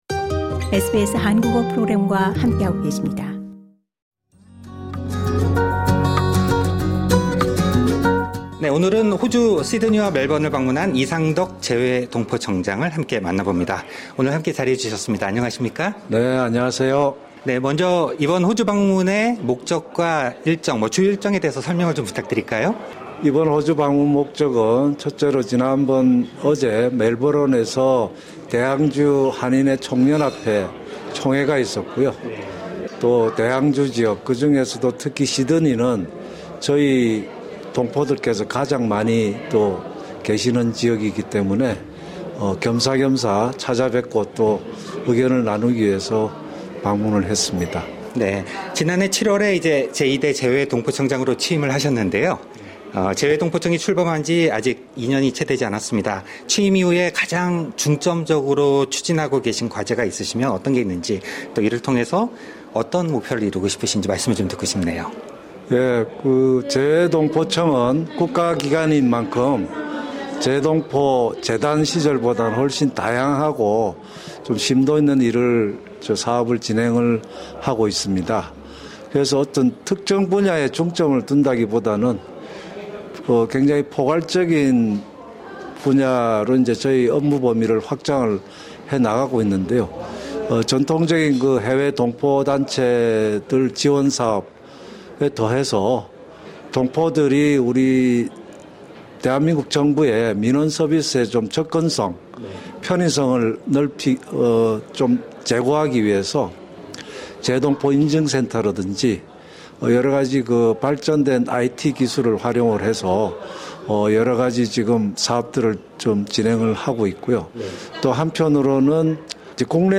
이상덕 재외동포청장 인터뷰: '음력설 예산 지원 방안에서 복수 국적 논의까지'